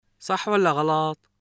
[Saħħ wəlla ġalaT]